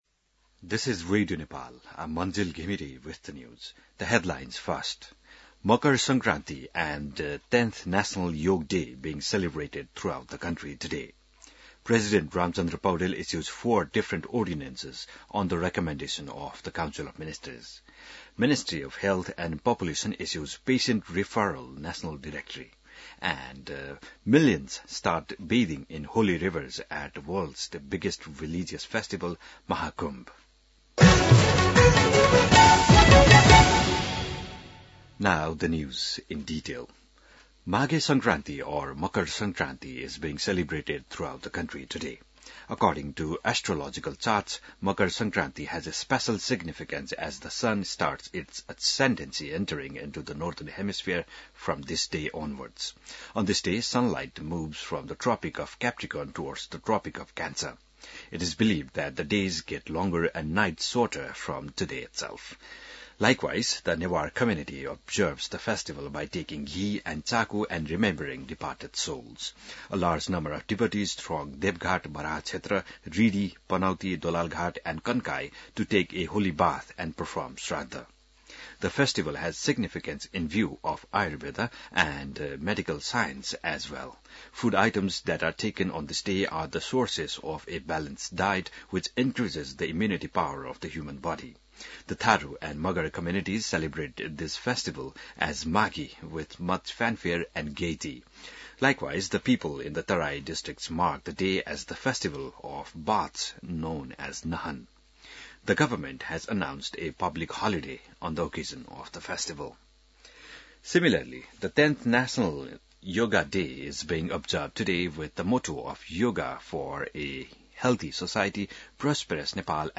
बिहान ८ बजेको अङ्ग्रेजी समाचार : २ माघ , २०८१